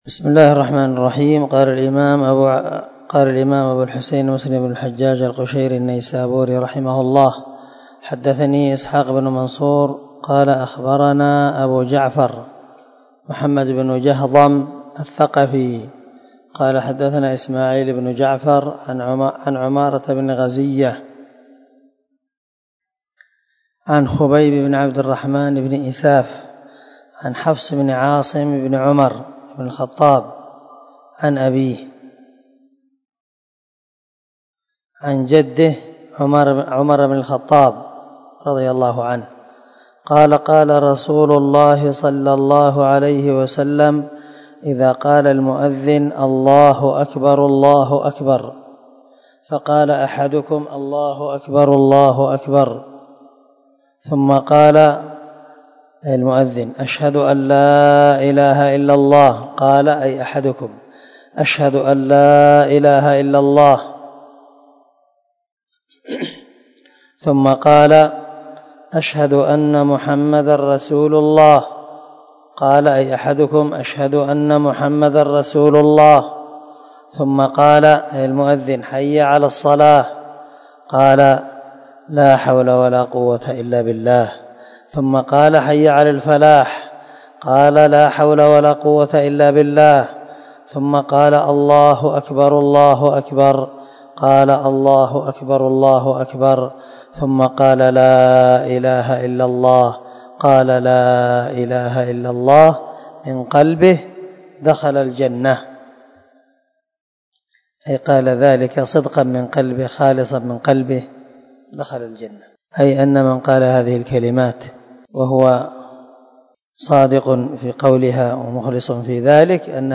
264الدرس 8 من شرح كتاب الصلاة حديث رقم ( 385 - 386 ) من صحيح مسلم